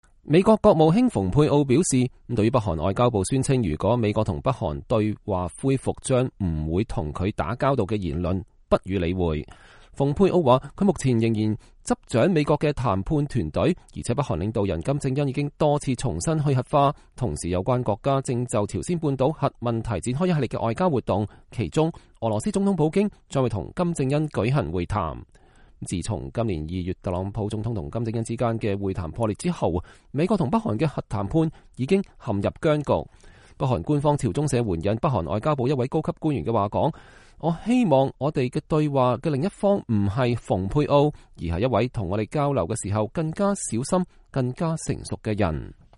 美國國務卿蓬佩奧和代理國防部長沙納漢在美國國務院與日本外相河野太郎、防衛大臣巖谷武舉行記者會。（2019年4月19日）